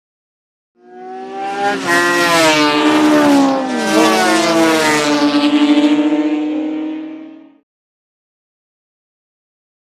Motorcycles; By; 500 Cc Motorbikes Past Mic. At Speed.